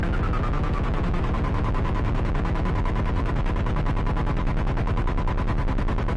描述：循环的节奏来自Moog rogue和Phototheremin的即兴创作
Tag: 即兴 循环 穆格 光特雷门 样品 空间 老虎